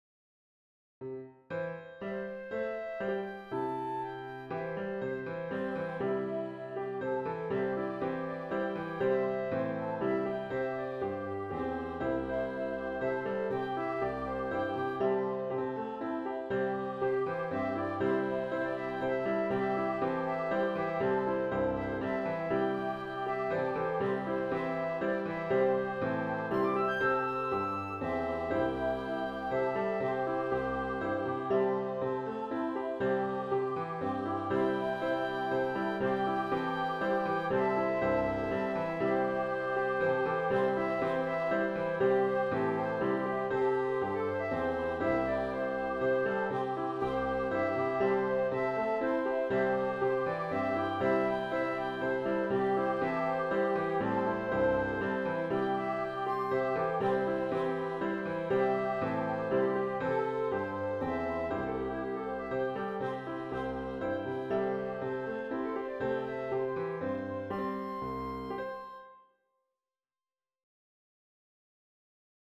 O Sing to the Lord 3 part round with flute v6 - Finding the Meaning in Everyday Life
O-Sing-to-the-Lord-3-part-round-with-flute-v6.wav